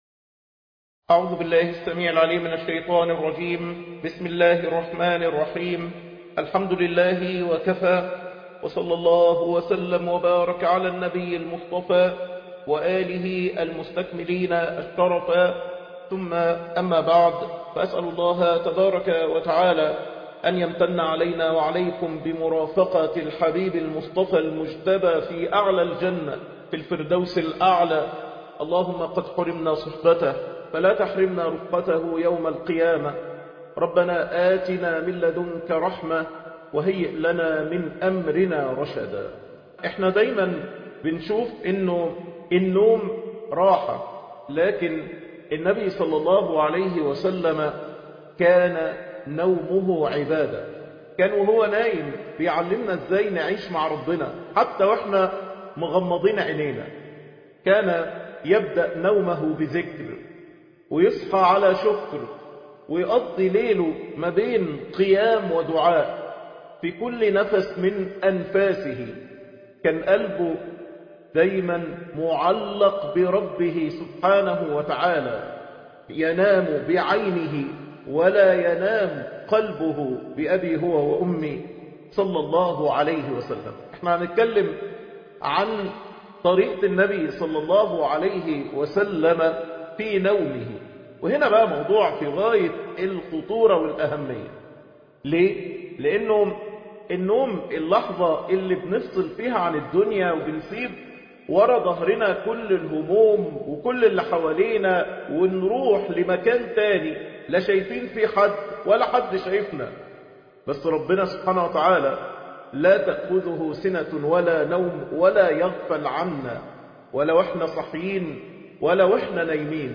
شرح الشمائل المحمدية _ الدرس 31 _ سنة ثلث العمر ..درس لا يفوتك